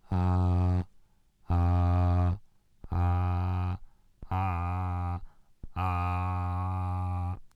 Hier is een voorbeeld van een klank die in vijf stappen verandert tot een klinker: